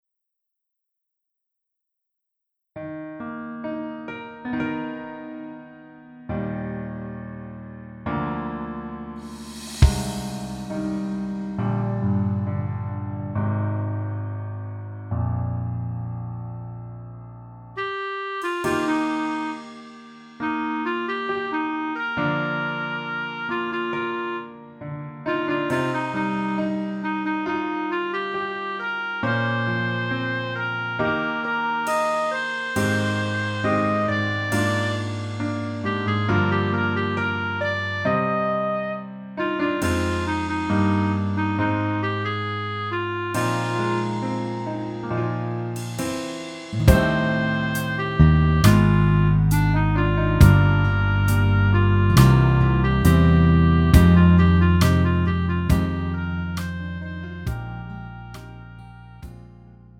음정 남자키
장르 가요 구분 Pro MR